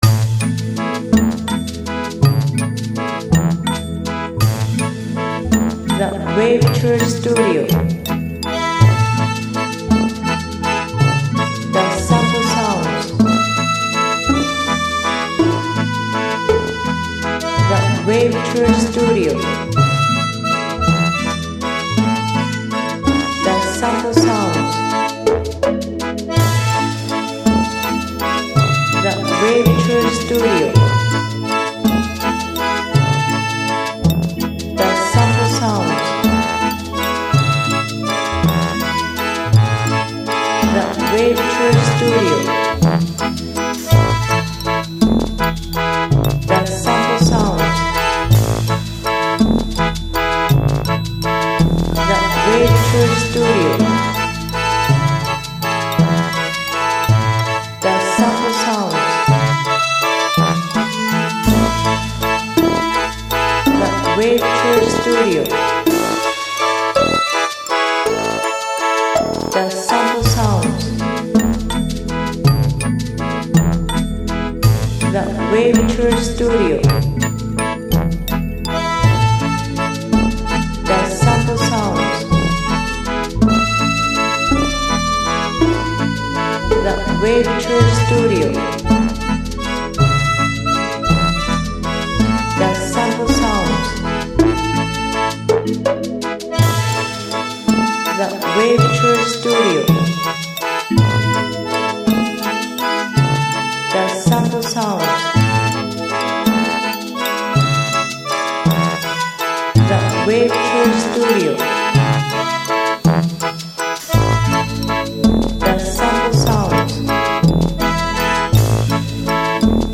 音源保護のためサンプルボイスが入っています。
164bpm